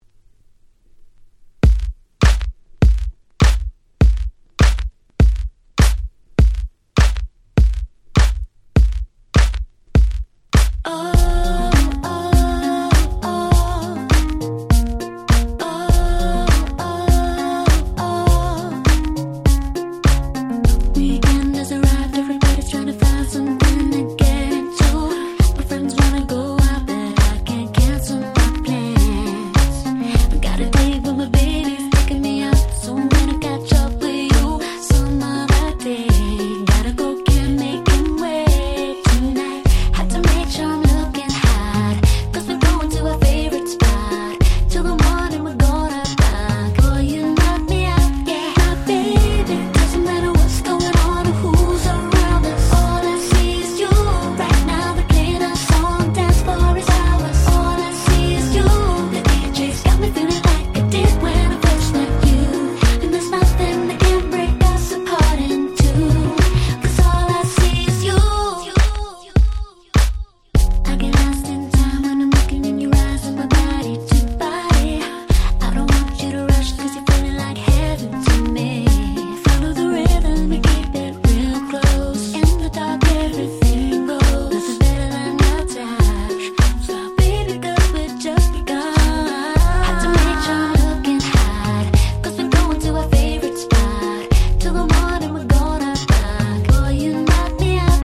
07' Super Nice R&B !!
キラキラでめちゃ良い曲！！